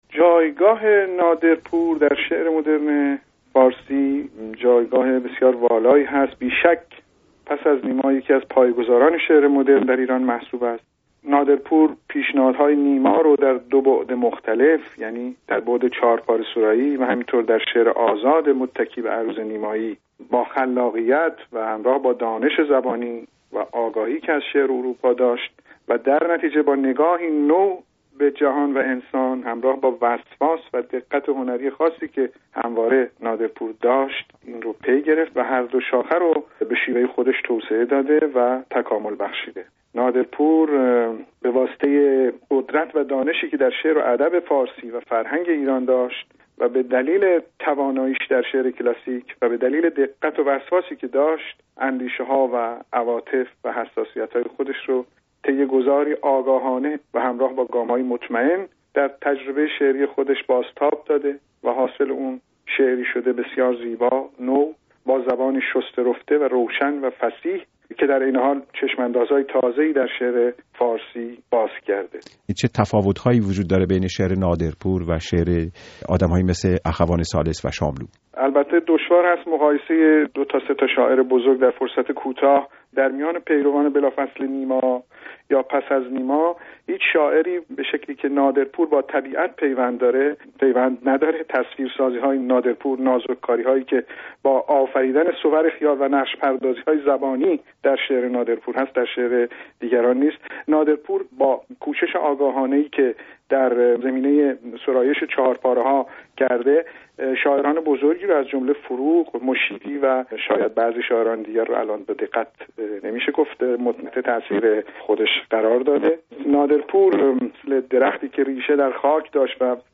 نادرپور و چشم‌اندازهای تازه در شعر فارسی در گفت‌وگو با م. سحر